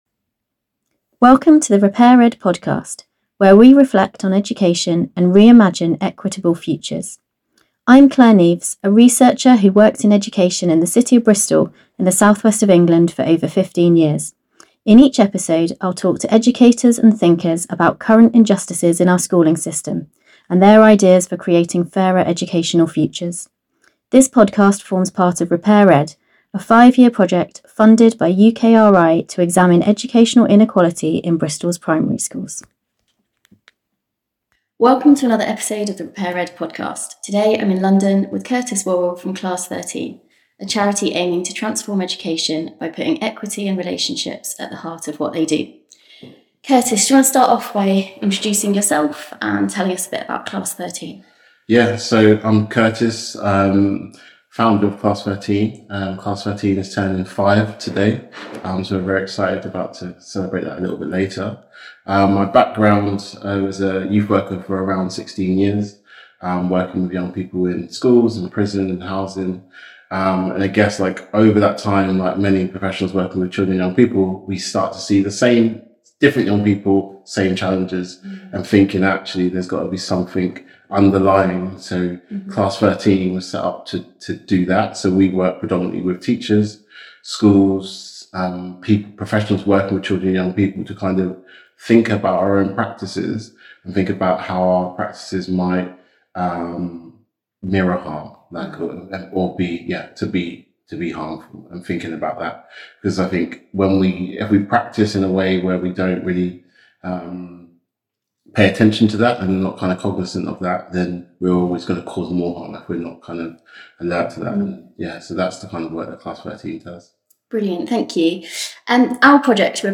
This episode was recorded in person, in Brixton, ahead of the launch of the Class 13 report 'An Argument for Possibility'.